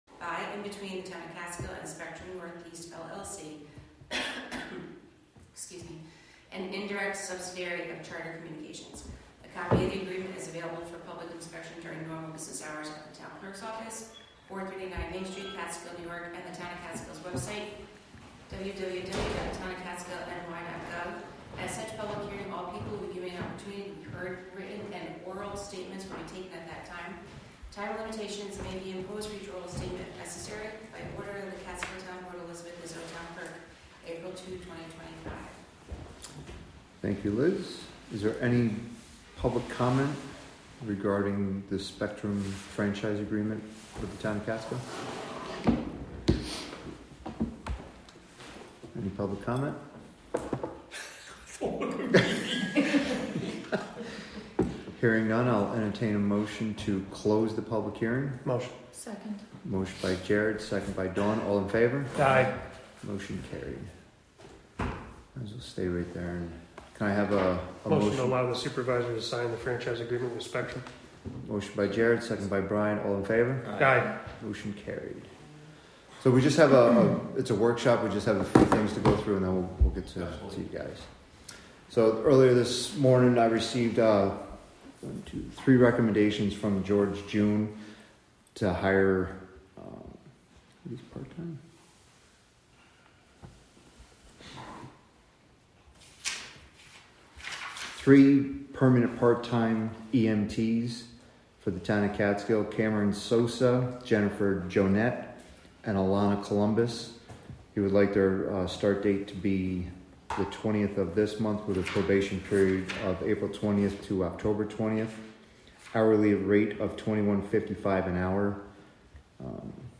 Live from the Town of Catskill: April 16, 2025 Catskill Town Board Meeting (Audio)